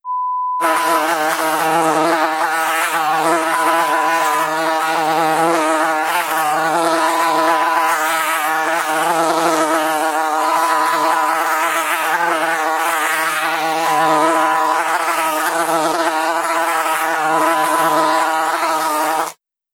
Mosca 2 (moscardón)
Sonido de moscardón revoloteando (zumbido). Acompaña a dicho sonido un pitido espaciado y constante no relacionado con dicha actividad
zumbido
insecto
Sonidos: Animales